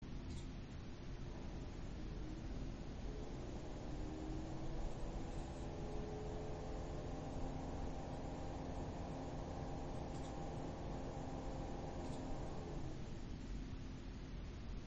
Ich habe anbei ein paar Aufnahmen gemacht (ca. 20cm Abstand):
• 3x jeden Fan einzeln mit ~1300rpm / 39%.
Lüfter 2 ist gut (der Mittlere).
fan2 1300rpm.mp3